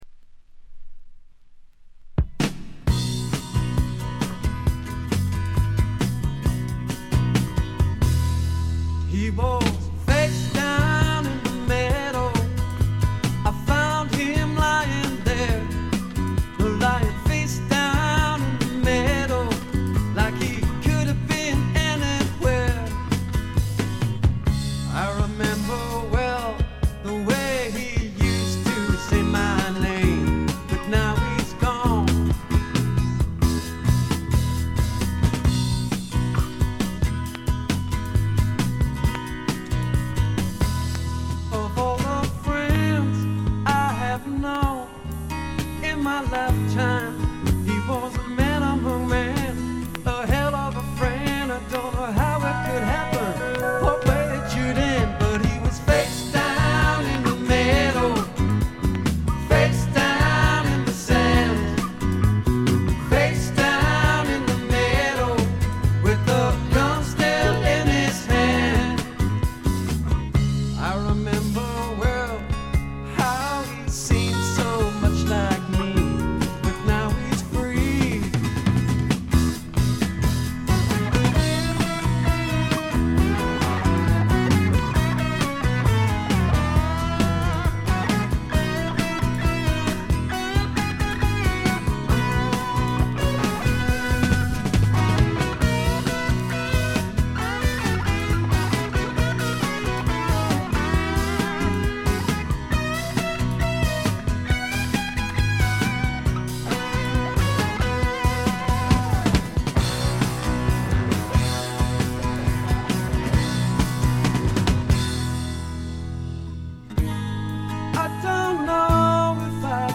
B1序盤軽く周回気味。
内容は身上である小気味良いロックンロール、軽快なフォークロック、メロディアスなポップ作等バラエティに富んだもの。
試聴曲は現品からの取り込み音源です。